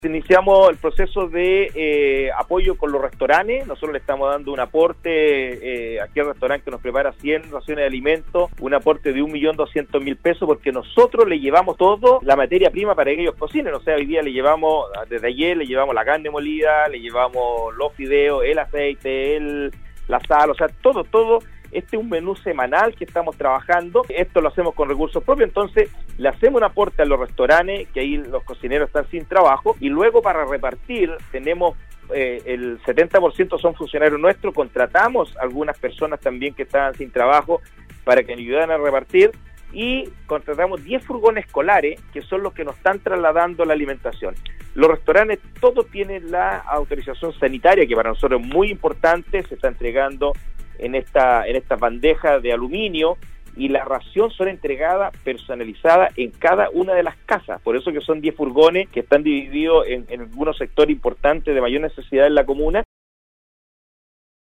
La tarde de este miércoles, el alcalde de Vallenar, Cristian Tapia sostuvo un contacto telefónico en la emisión del noticiero de Nostálgica donde se refirió a las raciones de almuerzos que se están entregando a las familias más vulnerables de la comuna.